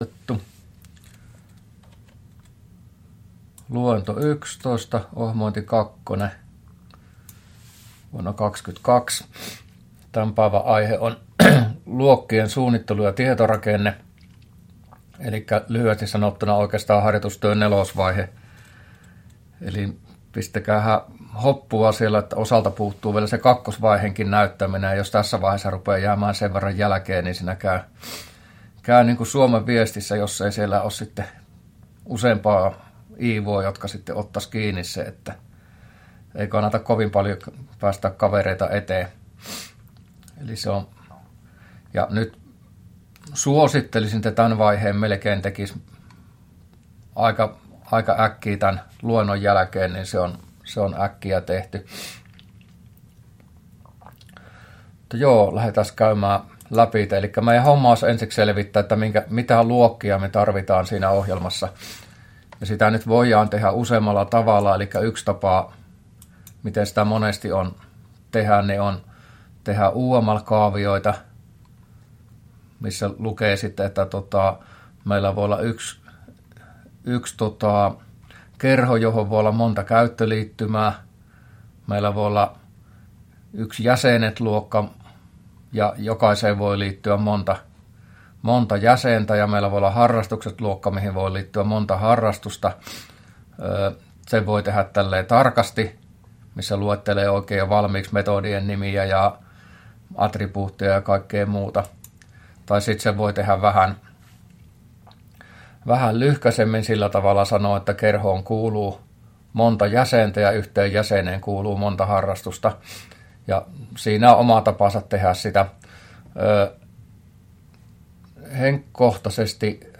luento11a